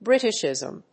音節Brít・ish・ìsm 発音記号・読み方
/‐ʃìzm(米国英語)/